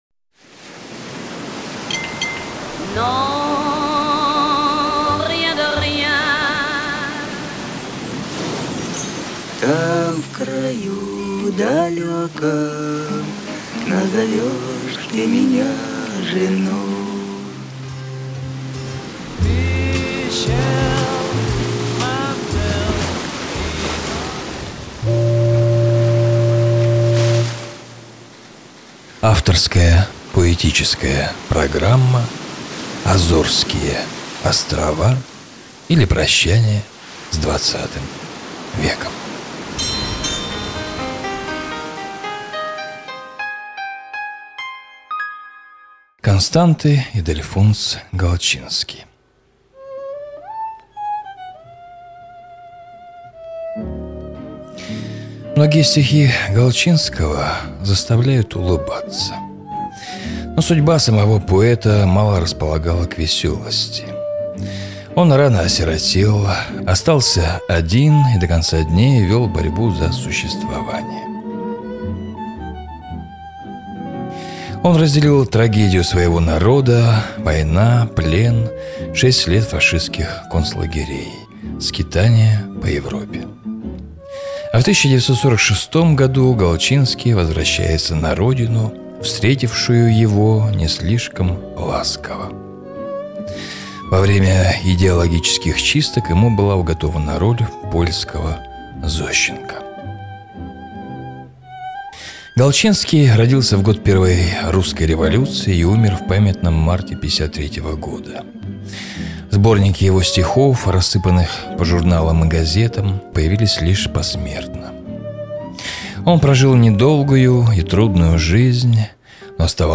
На протяжении достаточно долгого времени, начиная с 1999 года, на разных радиостанциях города* выходили мои авторские поэтические и литературно-музыкальные программы – «АЗОРСКИЕ ОСТРОВА, ИЛИ ПРОЩАНИЕ С ХХ ВЕКОМ…», «ЖАЖДА НАД РУЧЬЁМ», «НА СОН ГРЯДУЩИЙ», «ПолУночный КОВБОЙ», «ПОСЛУШАЙТЕ!».
Музыка – Стефана Граппелли
Музыка – Стефана Граппелли, Иогана Себасььяна баха